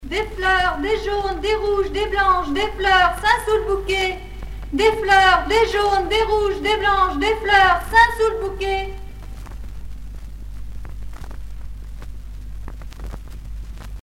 Cris de rue d'une marchande de fleurs